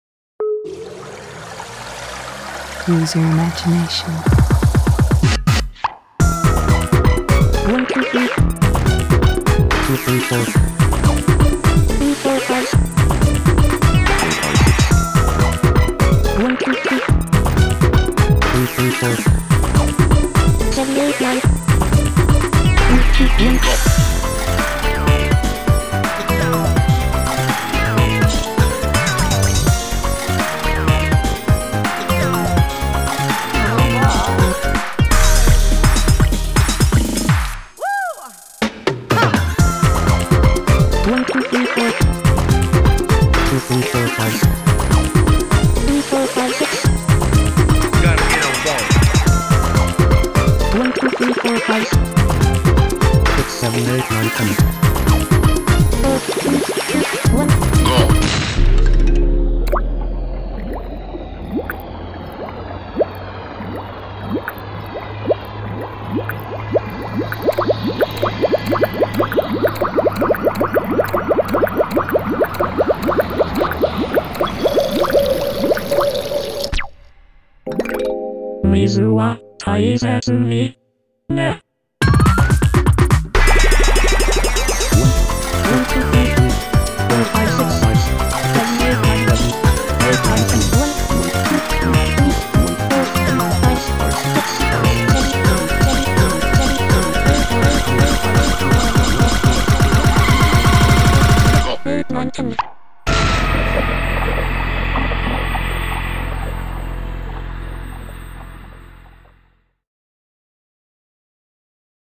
BPM62-400
Audio QualityPerfect (High Quality)
Genre: PERCUSSIVE 2.
Beware of the BPM changes.